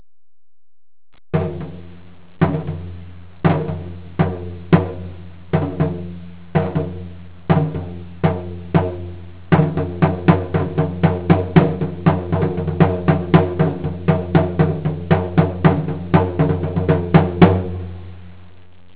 RENAISSANCE LONG DRUM
Long Drum Sound Clips